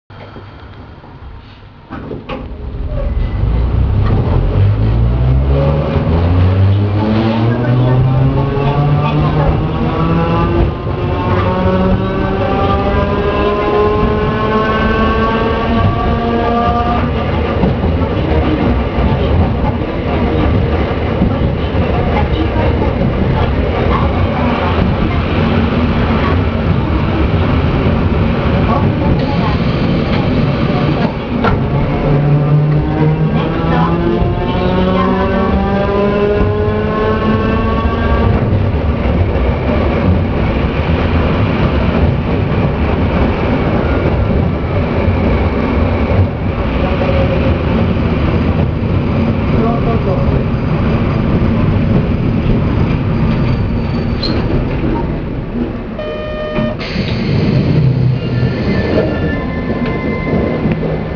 〜車両の音〜
・3300形走行音
【山鼻線】中央図書館前→石山通（1分1秒：333KB）
見た目自体は新しい車両ですが、車体更新車であるが故駆動装置は旧型車両そのまま。その為、吊り掛け式です。